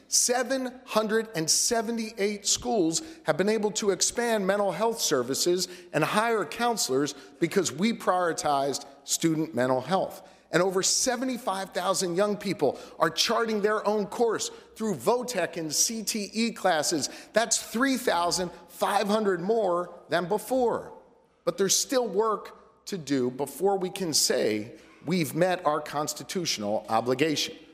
Governor Josh Shapiro presented his 2025-26 budget to the state General Assembly Tuesday afternoon, and he pledged to continue building on the last two years of progress.
The Governor said Pennsylvania’s schools are starting to benefit from the pinpoint focus on educational spending.